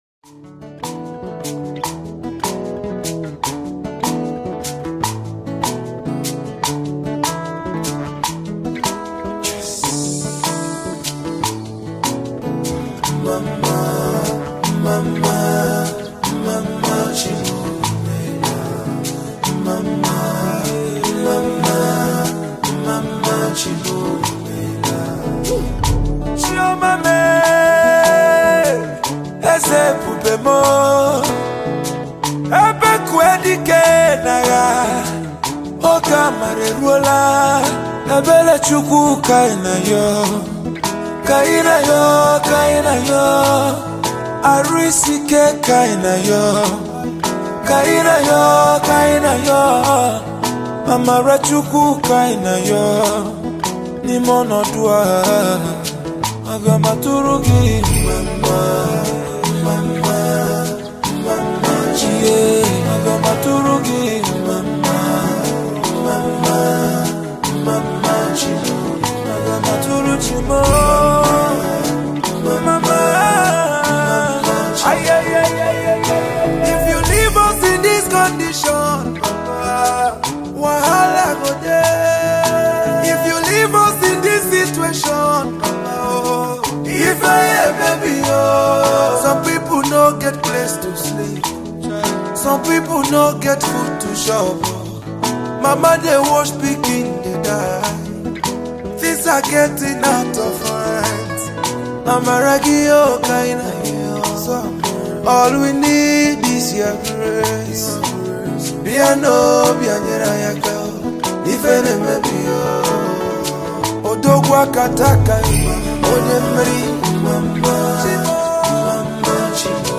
low tempo joint